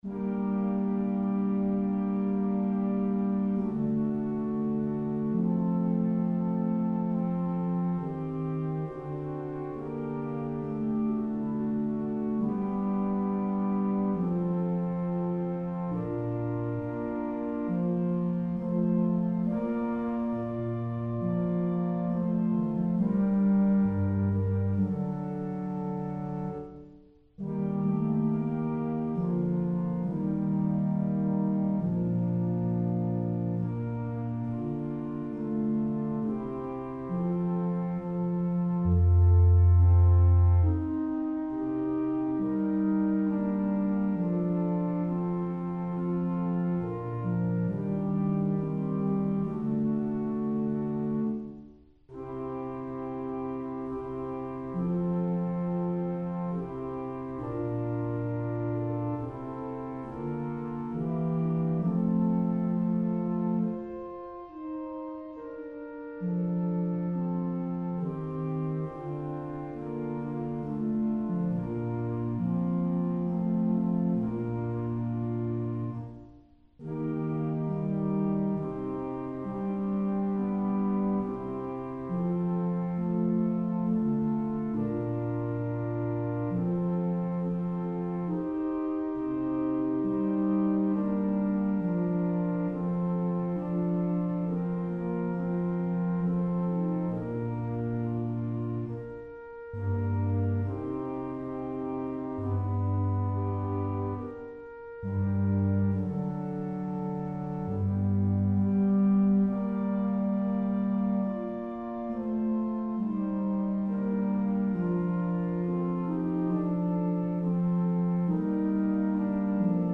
For the First Decade of prayers of The Chaplet of the Divine Mercy, click the ▶ button to listen to an organ setting of the polyphonic acapella composition Ave Verum Corpus by the Elizabethan English composer William Byrd (1540-1623), or play the music in a New Window